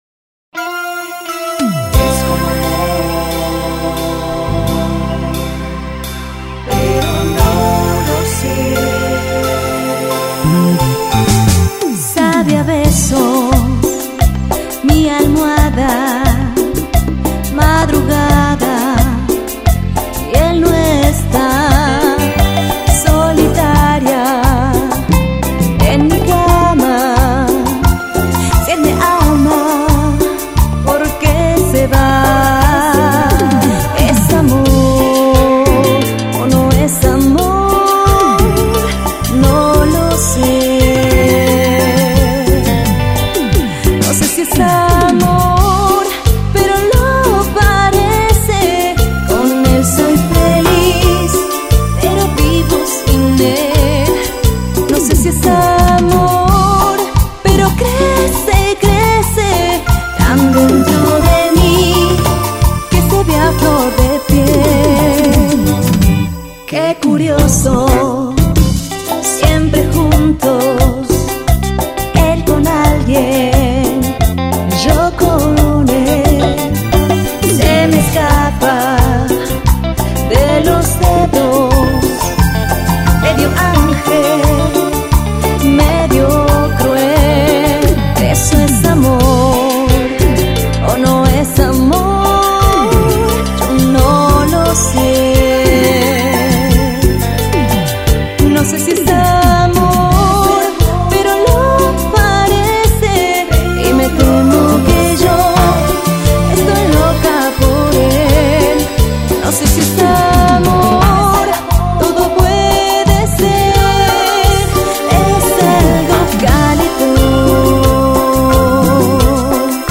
Llegó el momento de hacer CUMBIA !!